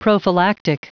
Prononciation du mot prophylactic en anglais (fichier audio)
Prononciation du mot : prophylactic